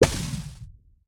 Minecraft Version Minecraft Version 1.21.5 Latest Release | Latest Snapshot 1.21.5 / assets / minecraft / sounds / entity / shulker_bullet / hit3.ogg Compare With Compare With Latest Release | Latest Snapshot